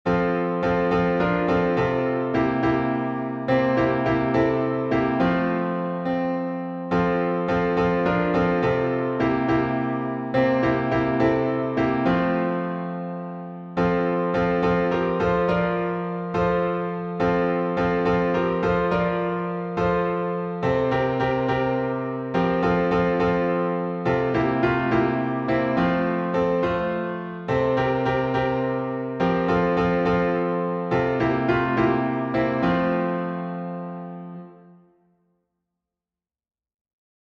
#4012: Wonderful Words of Life — alternate chording | Mobile Hymns